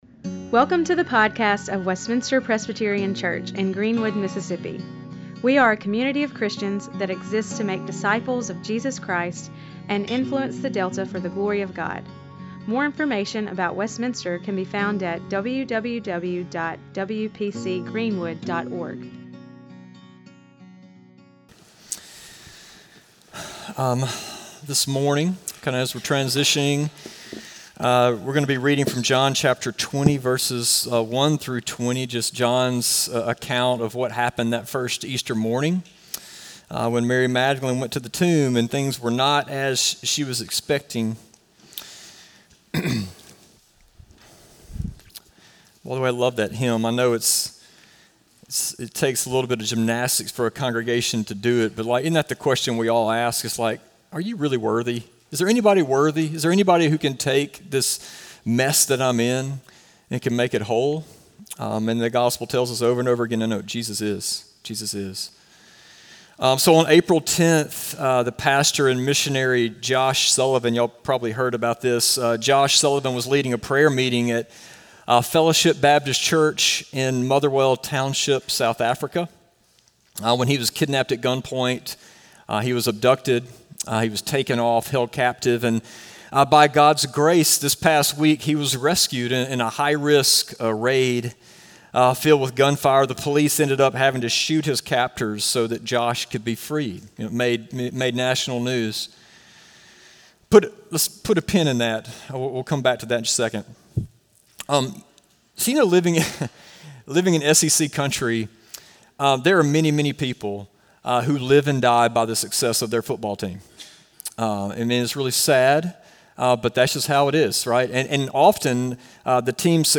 Sermons
sermon-4-20-25.mp3